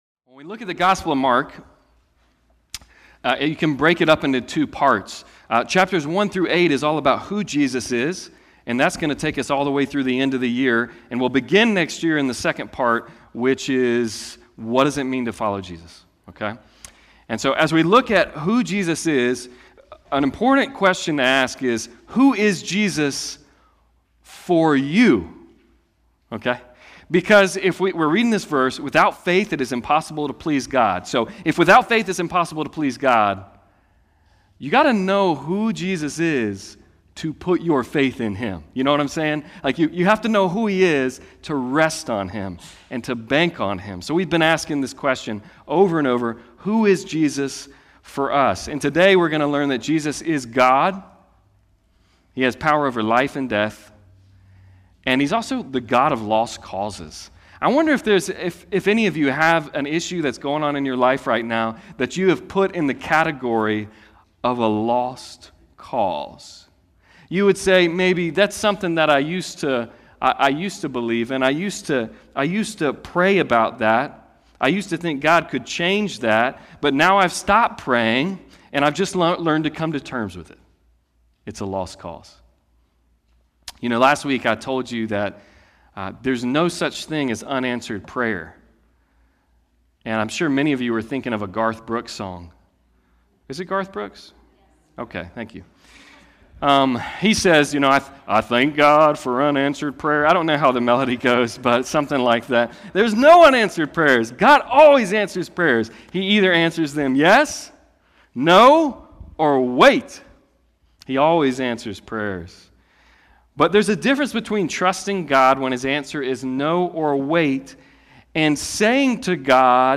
Passage: Mark 5:21-43 Service Type: Sunday Service
9.26.21-Sermon.mp3